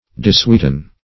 Dissweeten \Dis*sweet"en\, v. t. To deprive of sweetness.